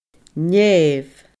nèamh /Nʲɛ̃ːv/